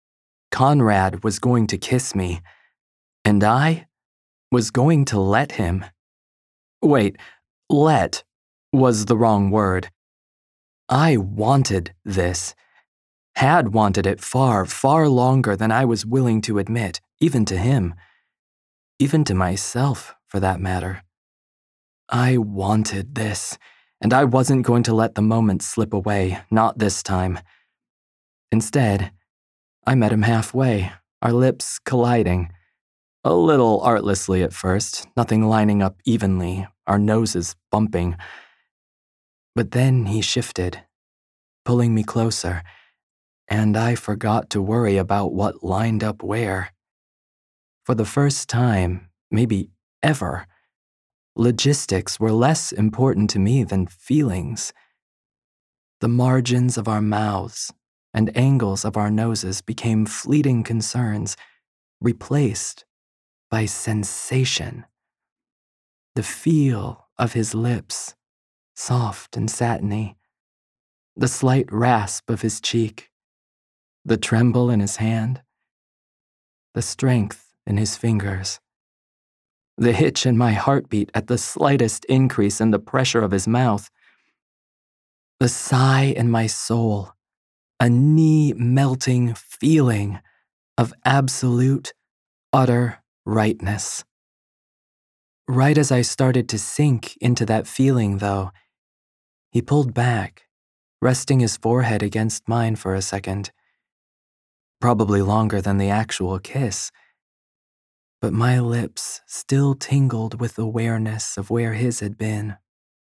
RomCom (1st person)
Man’s POV | download